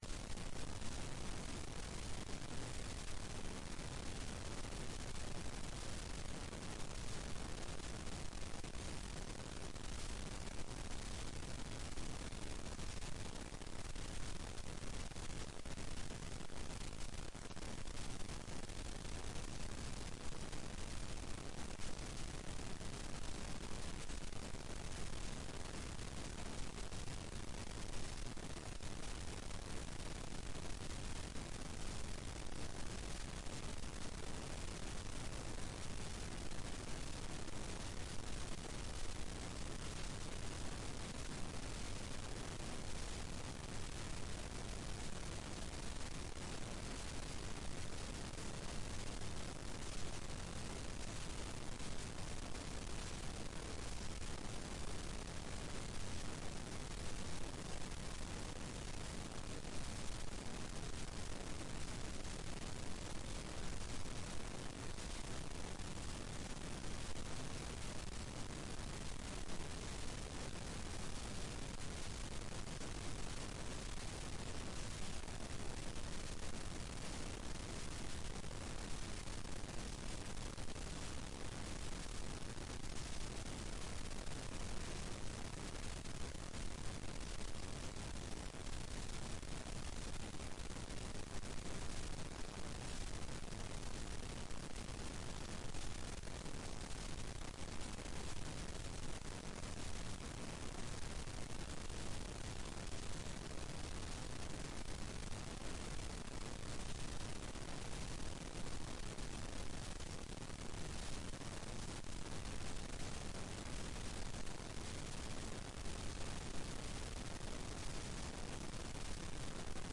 Rom: Store Eureka, 2/3 Eureka